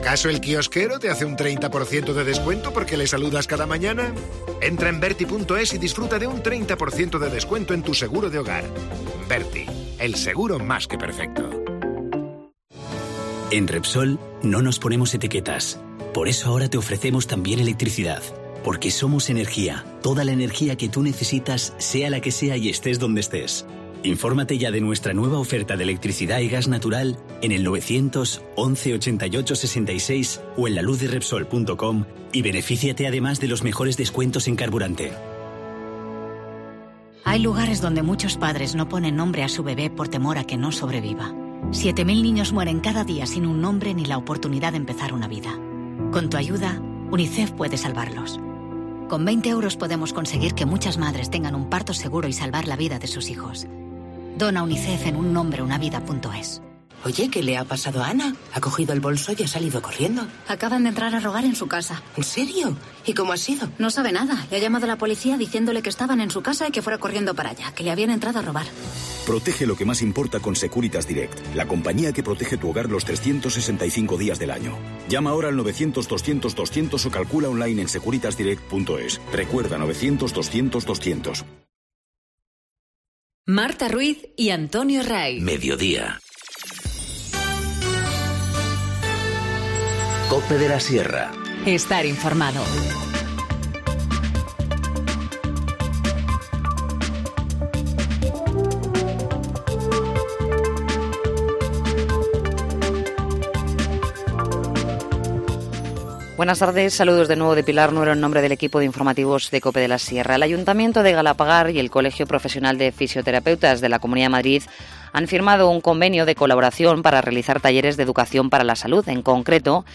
Informativo Mediodía 13 dic- 14:50h